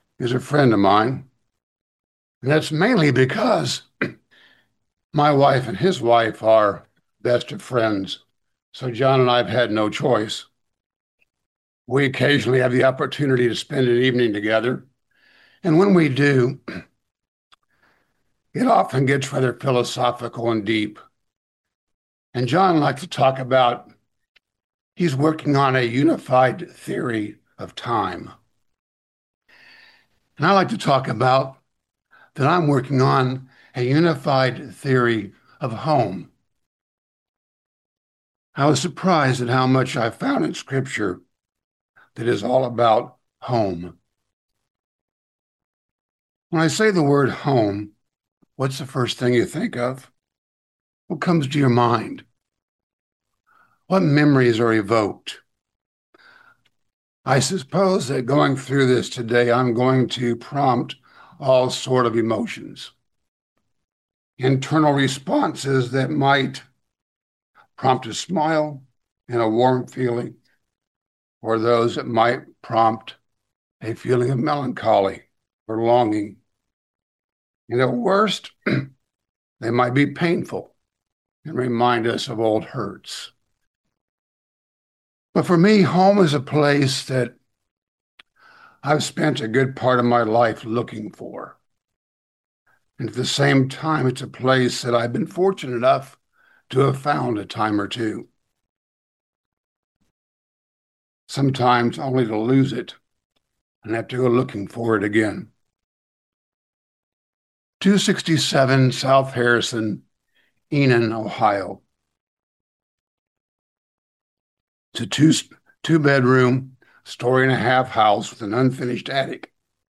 Sermons
Given in London, KY